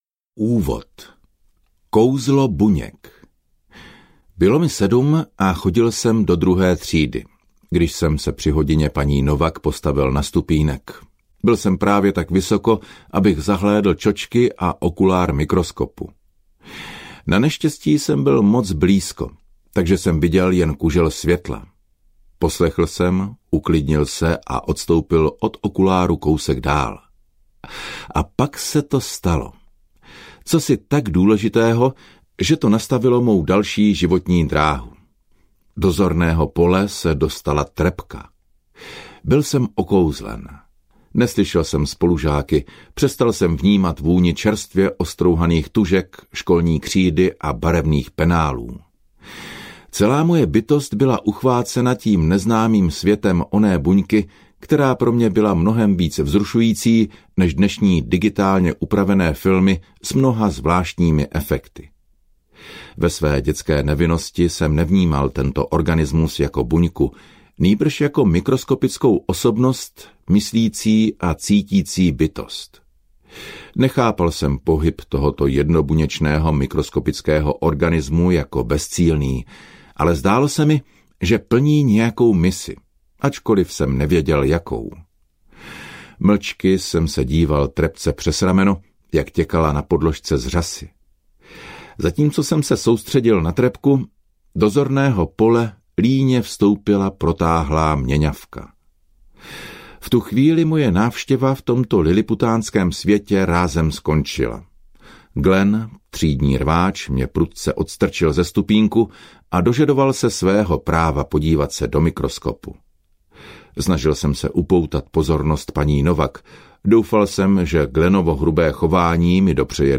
Audiokniha Biologie víry - Bruce Lipton | ProgresGuru